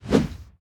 swing_big_b.ogg